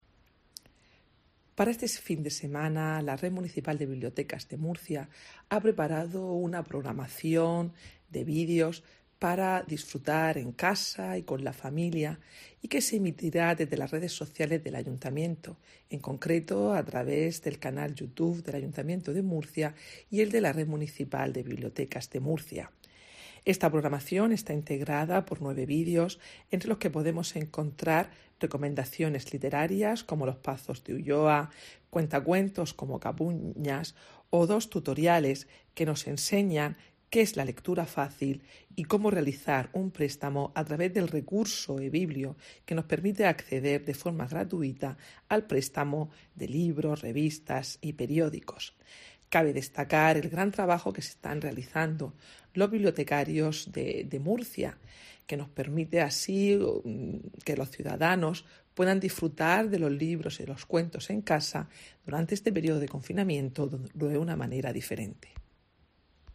Mercedes Bernabé, concejala de Agenda Urbana y Gobierno Abierto